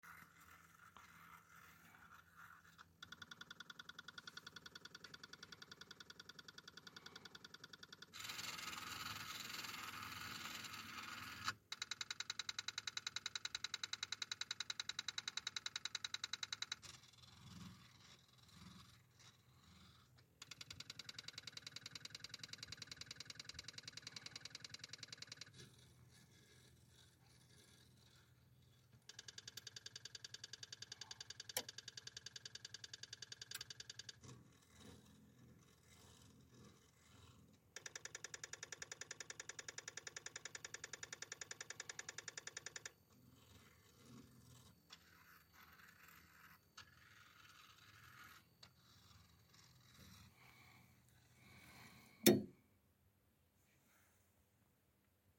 Sur l'audio clim 2 c'est au bout d'un certain temps les voyants s'allument en rouge.
On a l'impression qu'elle cherche à se lancer mais n'y arrive pas.
Dans le 2ème on alterne entre le fameux bruit de relaxation et des bruits bizarres ressemblant à une meule qui écrase du blé... vraiment étrange, et ça me laisse perplexe.